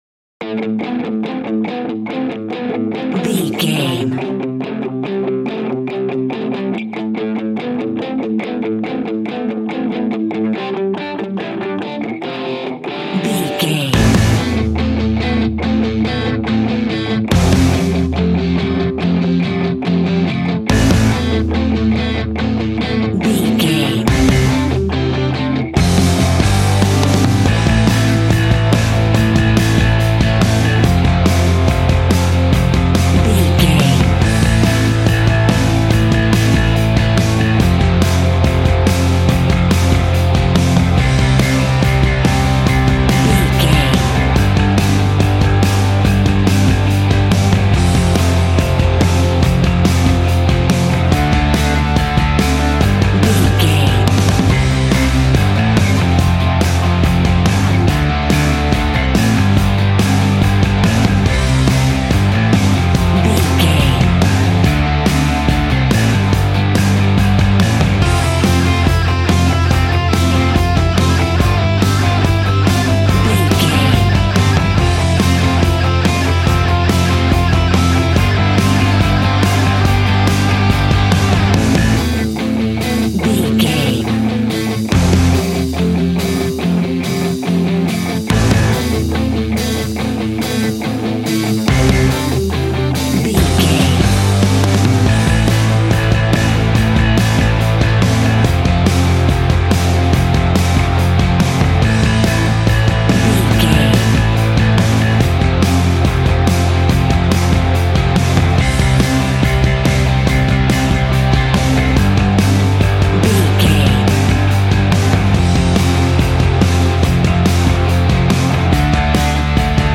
Ionian/Major
groovy
powerful
electric organ
drums
electric guitar
bass guitar